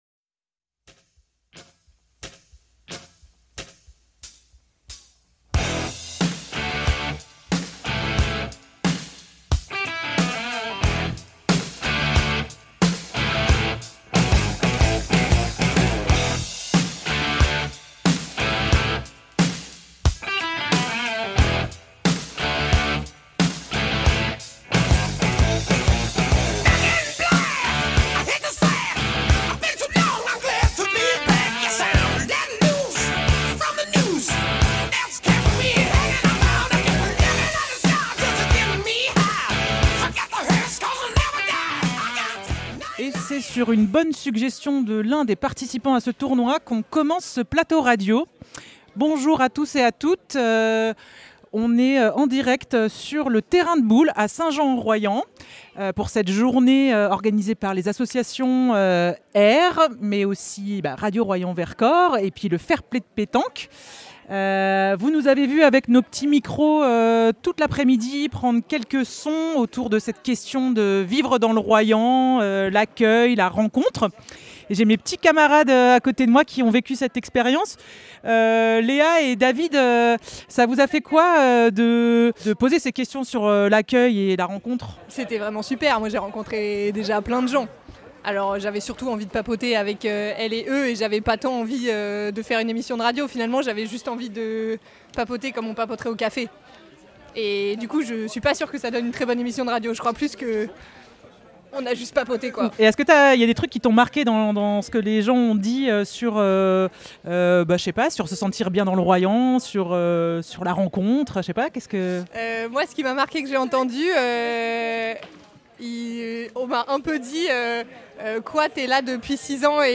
Nous avons baladé nos micros toute au long de l’après-midi pour demander aux personnes présentes ce qu’elles aiment dans le royans, échanger sur ces questions de l’accueil, de la rencontre et du lien entre les gens.